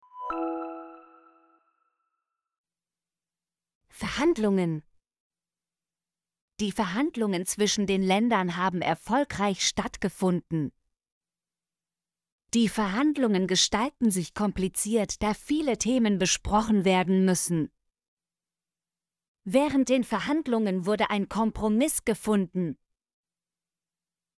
verhandlungen - Example Sentences & Pronunciation, German Frequency List